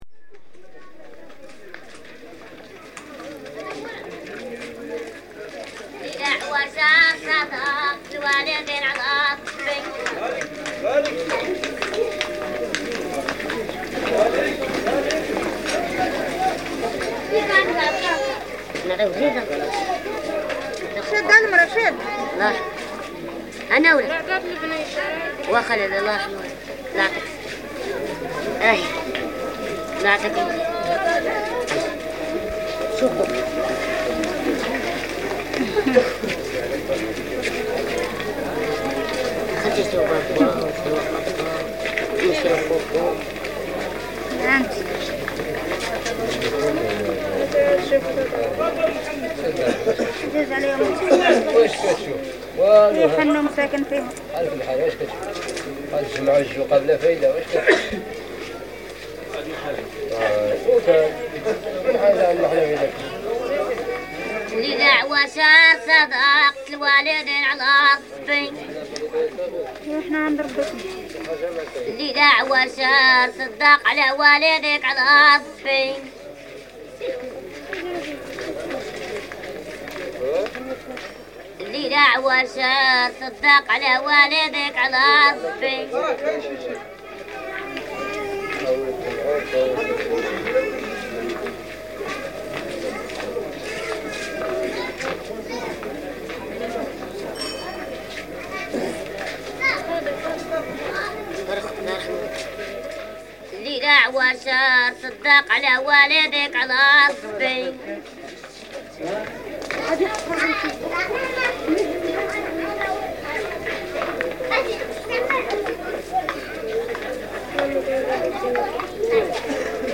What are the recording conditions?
From the sound collections of the Pitt Rivers Museum, University of Oxford, being from a collection of reel-to-reel tape recordings of Berber (Ait Haddidu) music and soundscapes made by members of the Oxford University Expedition to the Atlas Mountains of Southern Morocco in 1961.